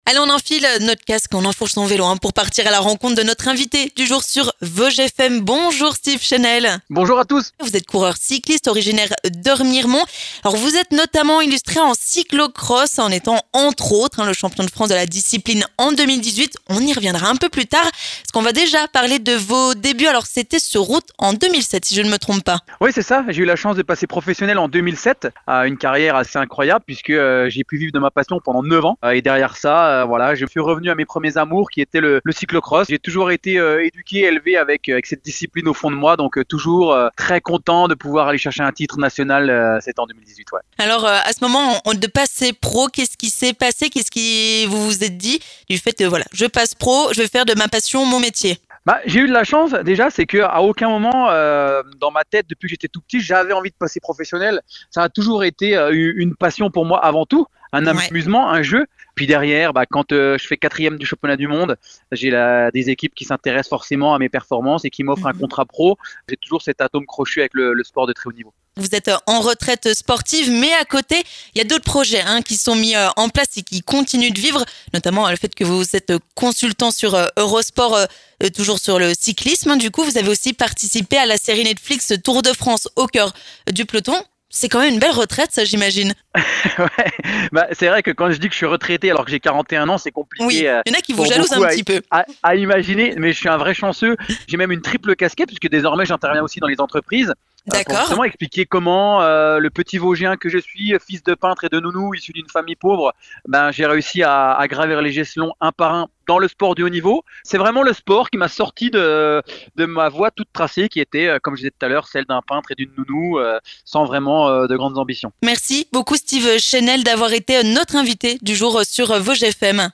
Steve Chainel est notre invité du jour sur Vosges FM !